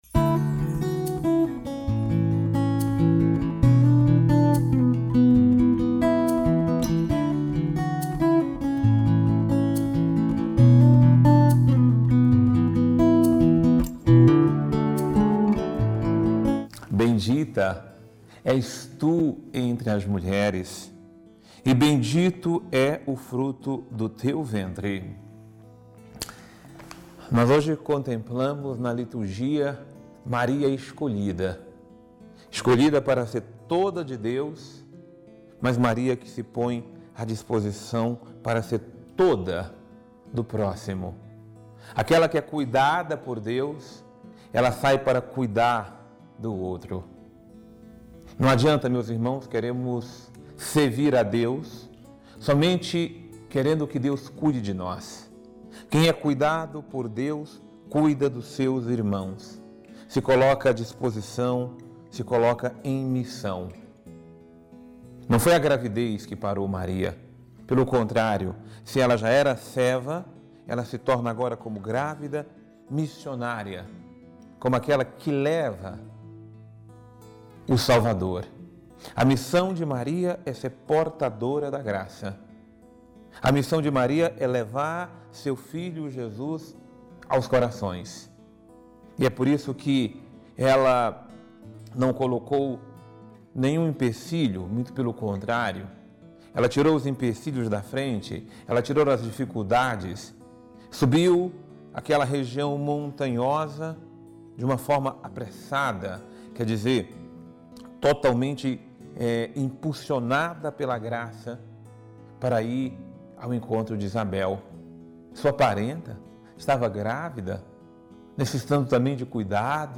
Homilia | A missão de Maria é levar Jesus ao seu coração